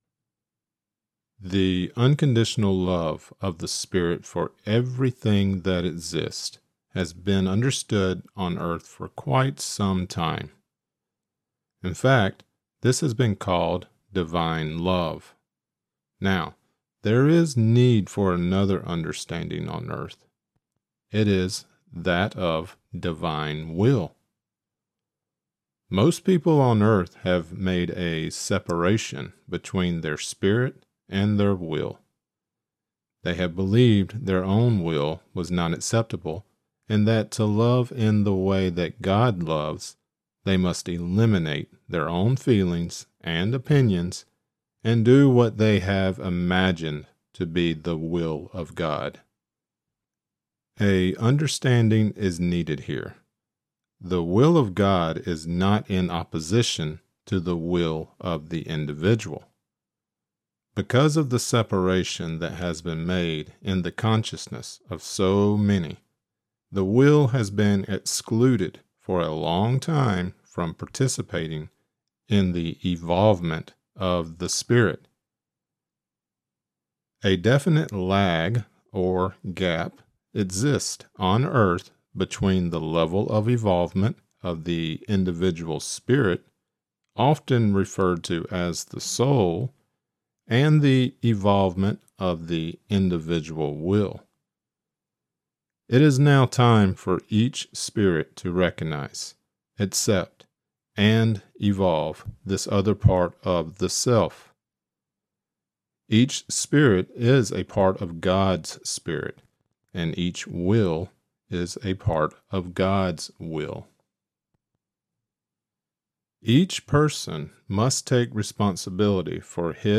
Lecture Created Transcript Blockchain Introduction 08/20/2025 Introduction (audio only) 08/20/2025 Watch lecture: View 2025 Lectures View All Lectures